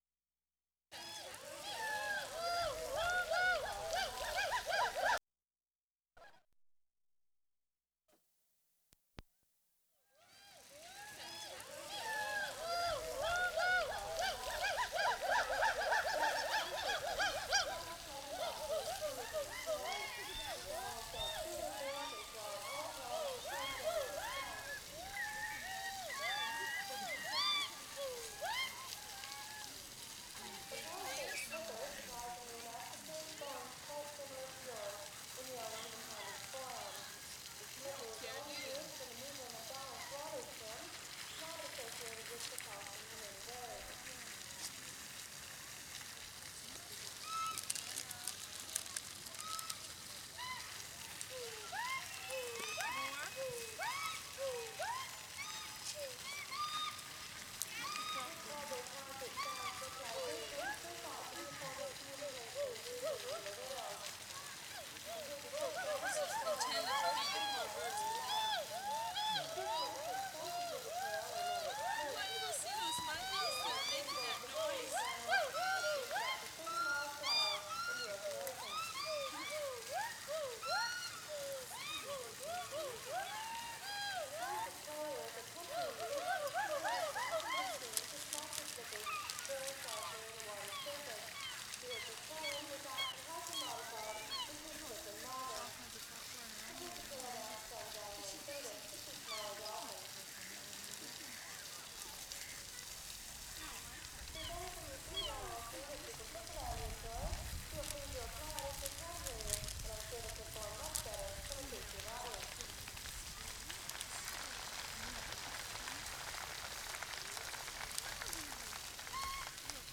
WORLD SOUNDSCAPE PROJECT TAPE LIBRARY
7. Gibbons again with whale show talk and whale sounds in the background. People reaching to gibbons, fountain in background.